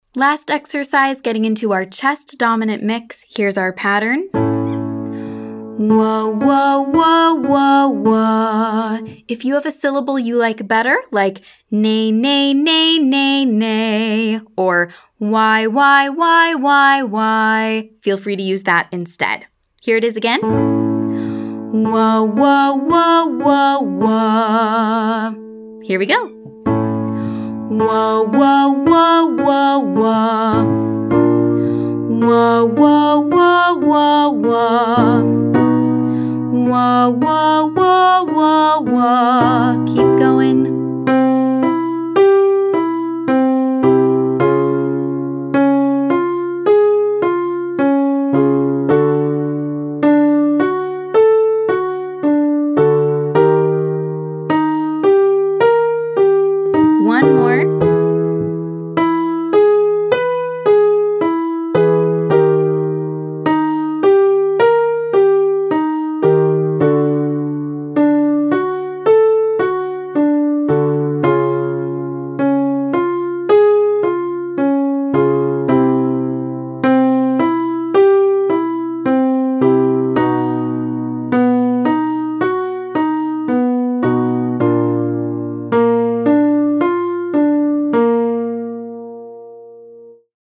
Quick warmup
Exercise 5: Mix (you choose syllable) 13531